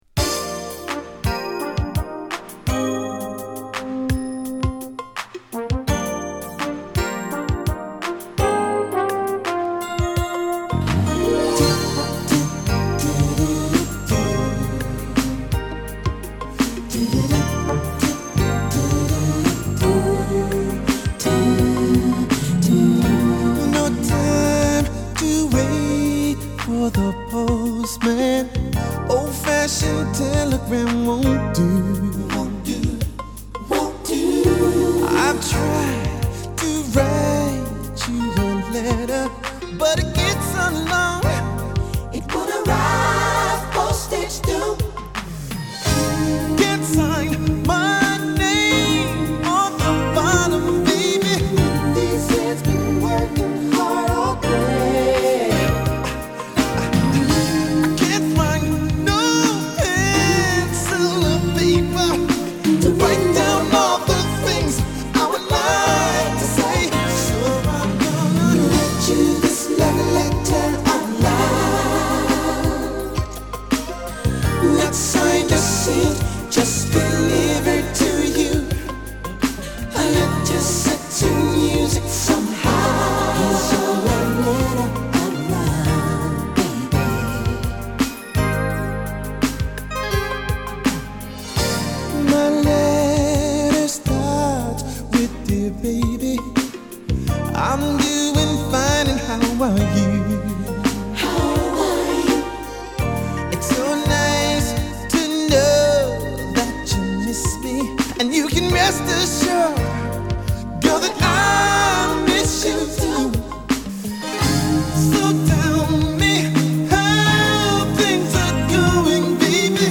NJSのハネた感がより出た1枚だが